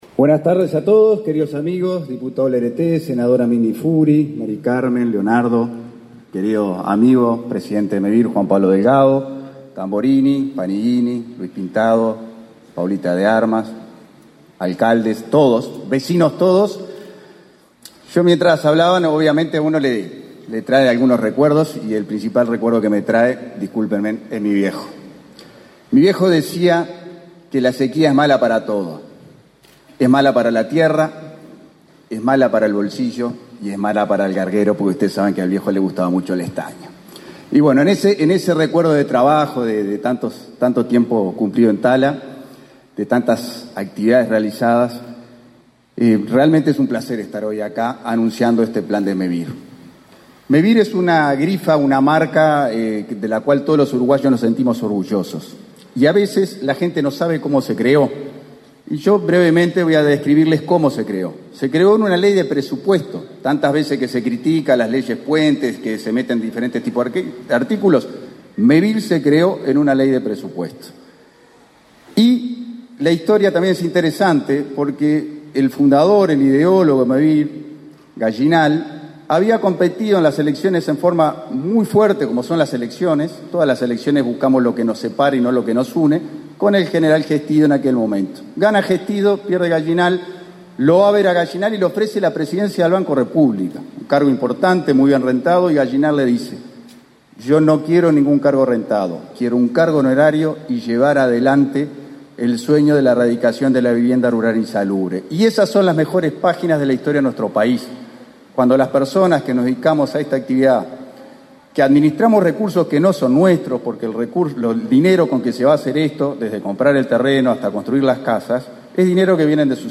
Acto por anuncio de nuevos planes de Mevir en Tala
Participaron del evento, el subsecretario de Vivienda y Ordenamiento Territorial, Tabaré Hackenbruch, y el presidente de Mevir, Juan Pablo Delgado.